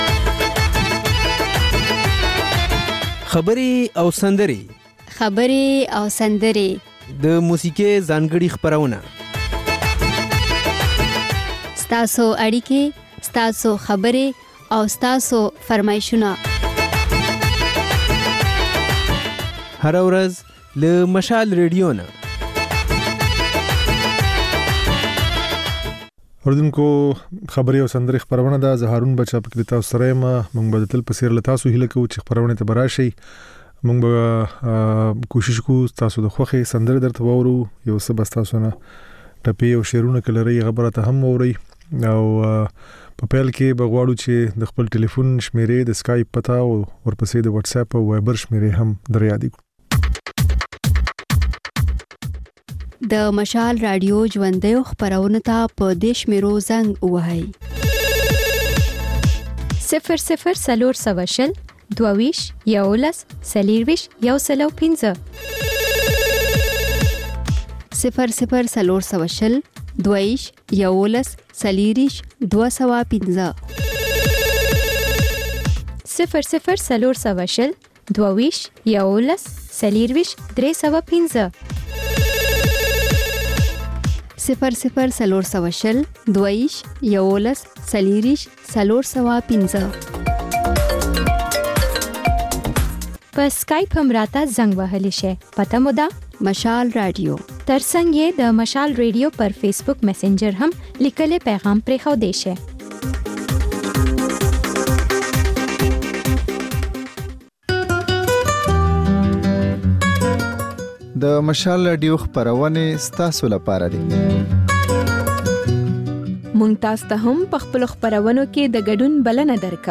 په دې خپرونه کې له اورېدونکو سره خبرې کېږي، د هغوی پیغامونه خپرېږي او د هغوی د سندرو فرمایشونه پوره کېږي. دا یو ساعته خپرونه د پېښور پر وخت سهار پر څلور او د کابل پر درې نیمو بجو تکرار خپرېږي.